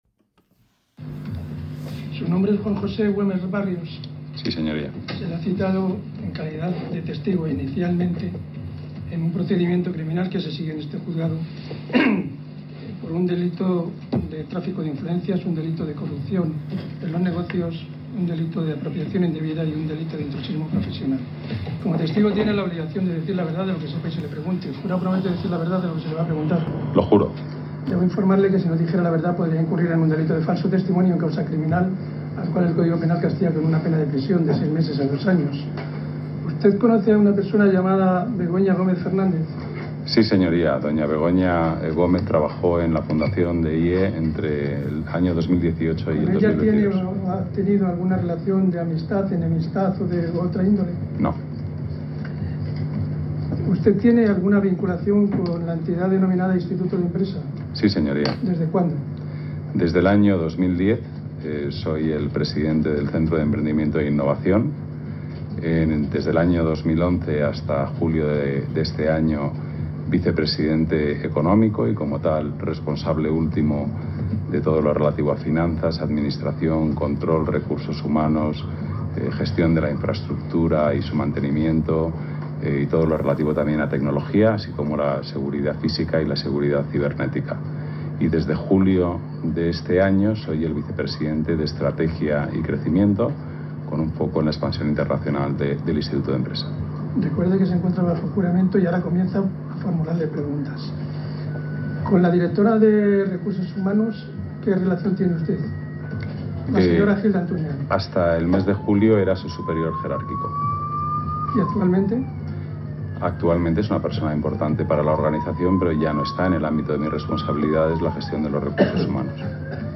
Escuchen en el audio de la declaración de Güemes del 18 de noviembre la conducta del juez Peinado, ¡es la bomba!